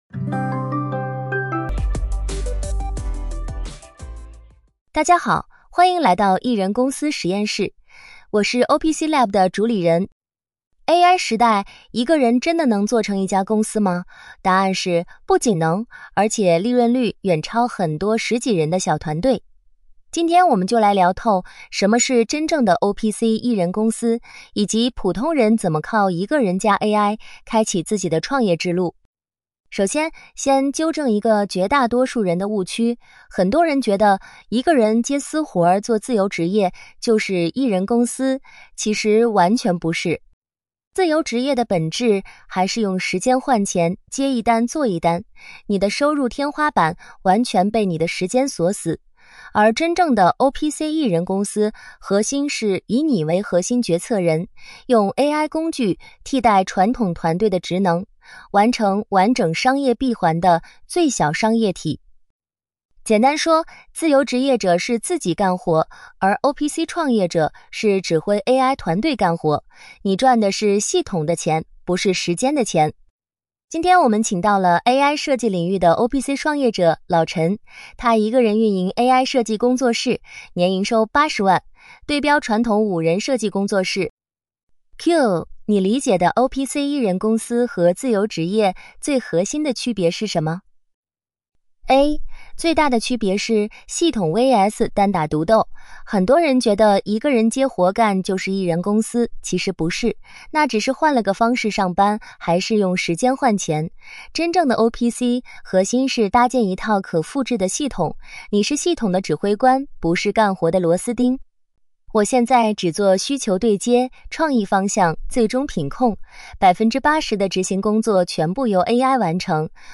OPC 创业者访谈专栏
每一期都是一次 OPC 创业者的深度对话